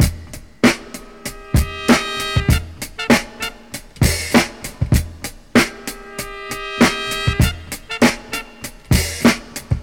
Drum loops
Original creative-commons licensed sounds for DJ's and music producers, recorded with high quality studio microphones.
97-bpm-fresh-drum-loop-sample-d-sharp-key-ckr.wav